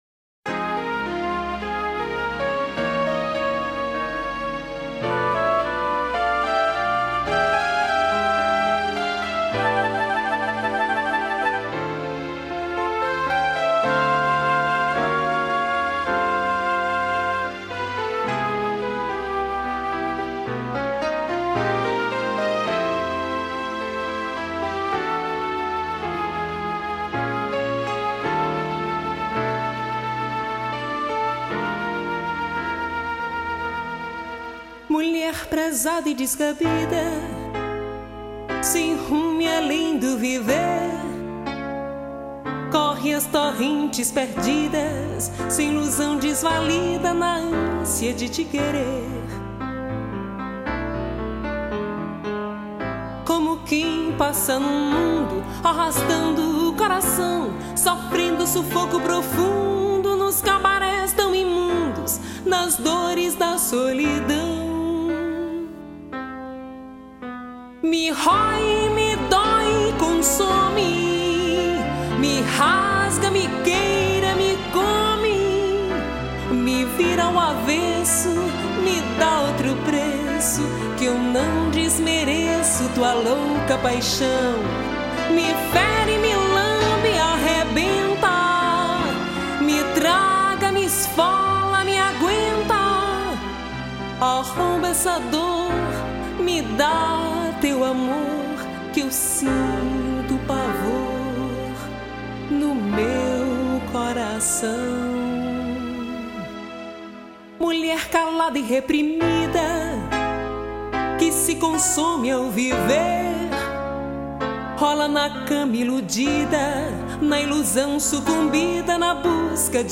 84   03:47:00   Faixa:     Canção
Voz